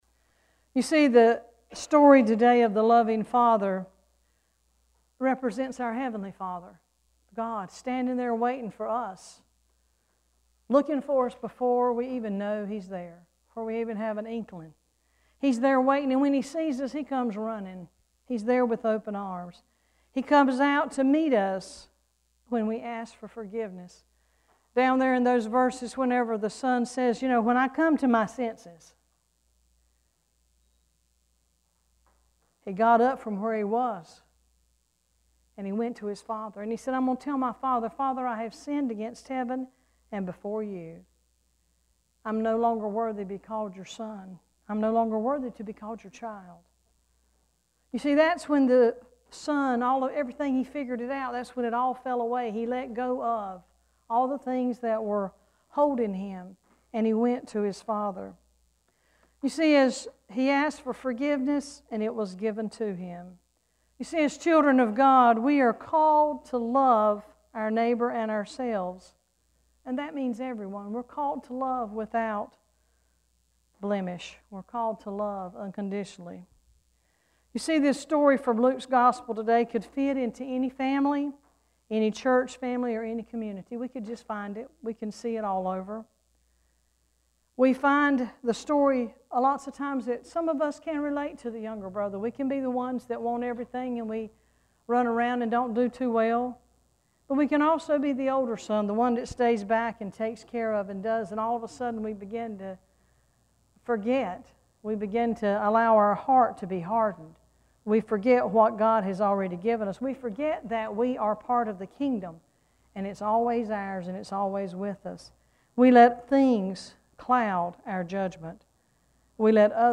9-17-sermon.mp3